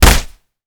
kick_hard_impact_04.wav